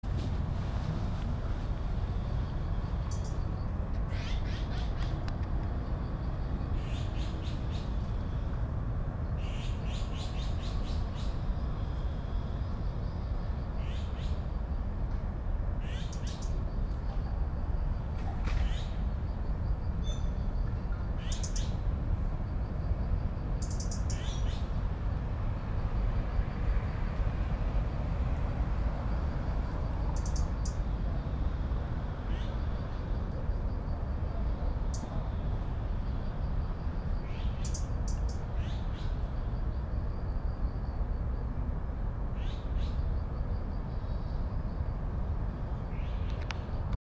It was clear that it belonged to a chat-like bird given the high-pitched, ventriloquist ‘sjeee’ notes similar to e.g. Black Redstart Phoenicurus ochruros. These notes were followed by a series of harsh ‘tek’ sounds. The call came from a small patch of bamboo.